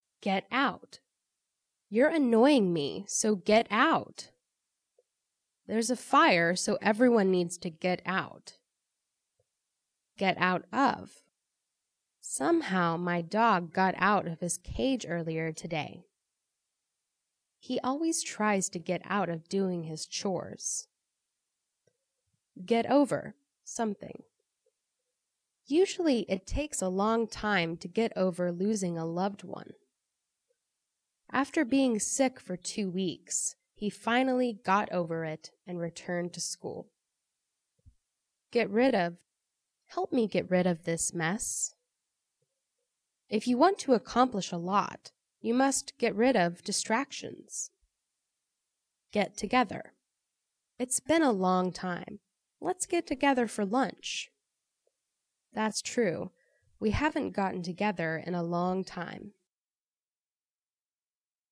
Audio – Versión Lenta